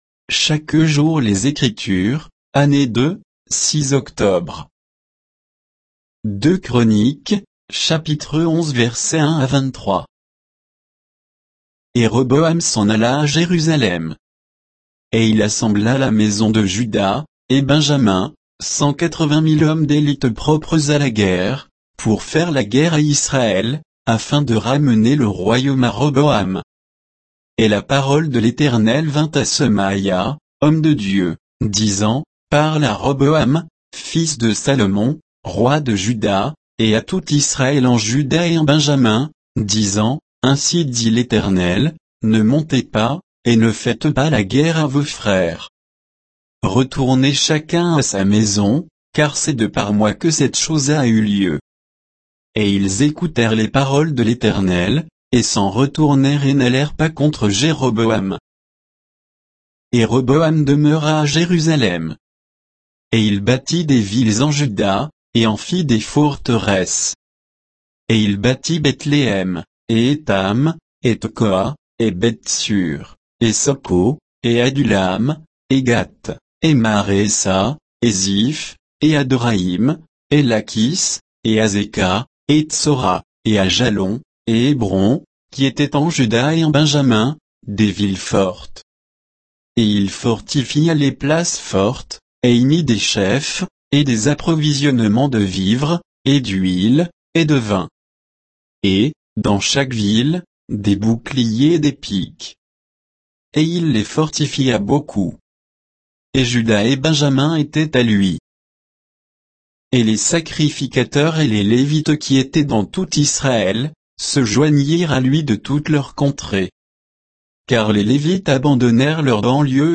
Méditation quoditienne de Chaque jour les Écritures sur 2 Chroniques 11, 1 à 23